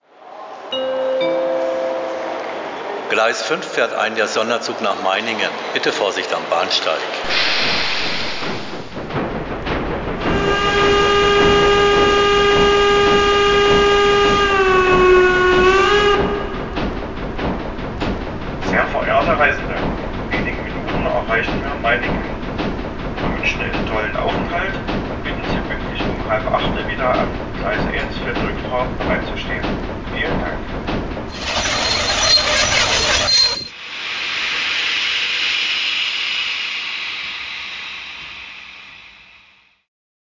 • Mit Spielewelt-Decoder mfx+ und umfangreichen Licht- und Soundfunktionen.